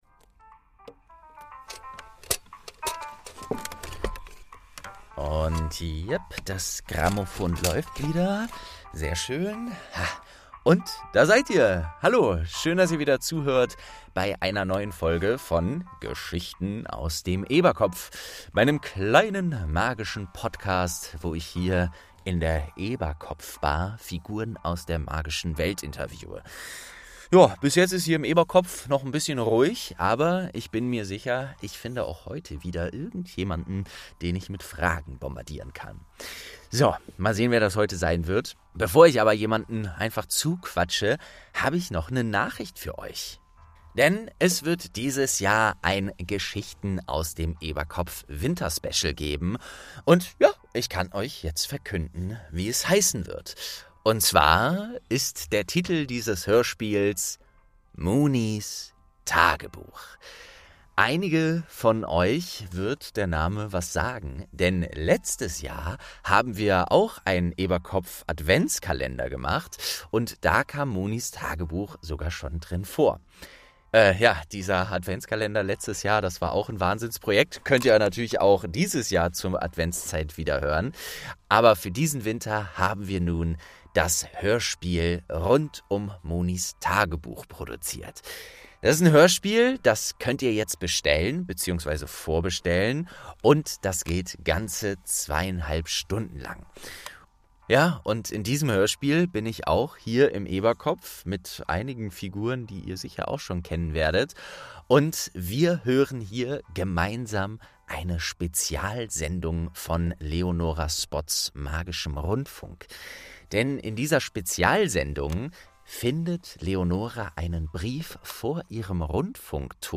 21. Mrs. Figg | St. 2 ~ Geschichten aus dem Eberkopf - Ein Harry Potter Hörspiel-Podcast Podcast